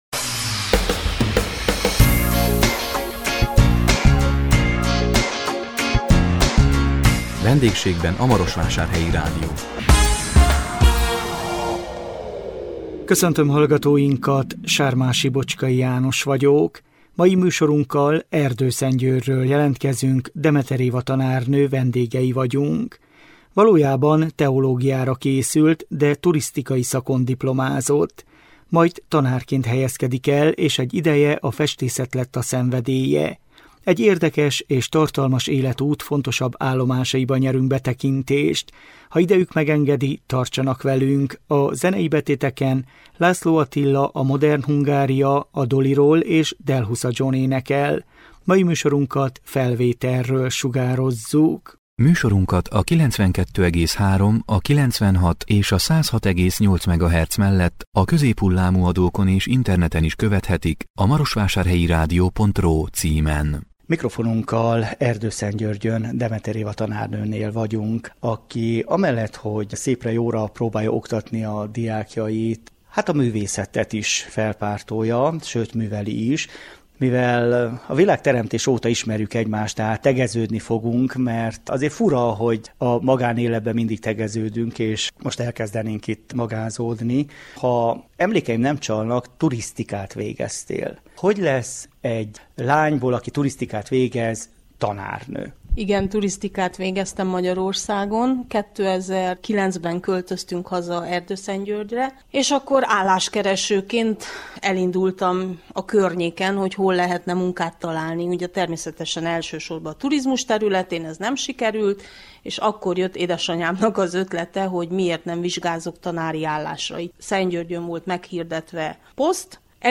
A 2024 november 14-én közvetített VENDÉGSÉGBEN A MAROSVÁSÁRHELYI RÁDIÓ című műsorunkkal Erdőszentgyörgyről jelentkeztünk